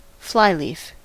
Ääntäminen
Ääntäminen US Tuntematon aksentti: IPA : /ˈflaɪˌlif/ Haettu sana löytyi näillä lähdekielillä: englanti Käännös Substantiivit 1. tyhjä sivu Määritelmät Substantiivit A blank page at the front or back of a book .